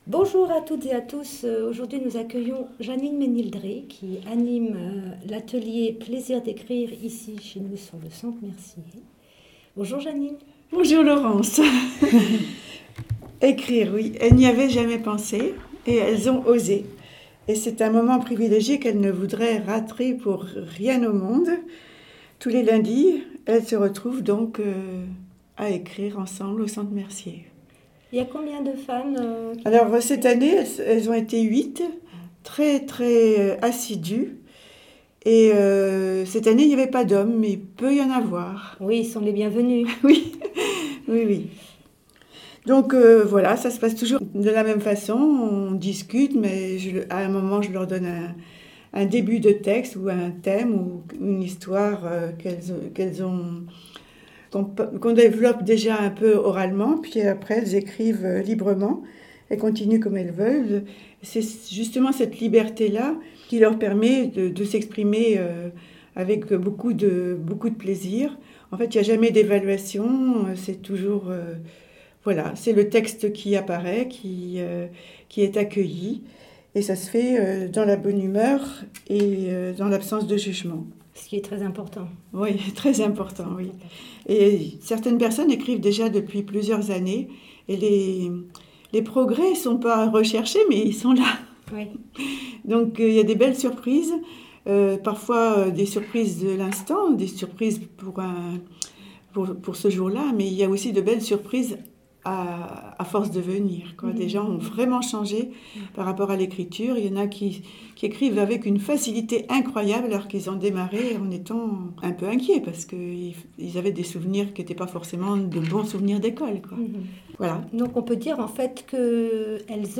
Le groupe "plaisirs d'écrire" du centre social Mersier ouvre les portes du studio MDR pour nous lire leurs plus beaux textes...